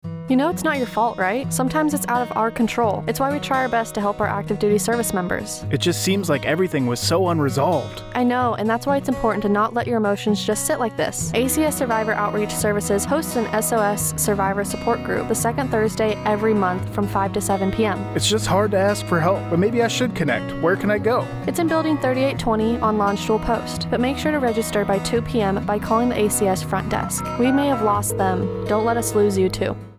Radio Spot - SOS Survivor Support Group AFN Kaiserslautern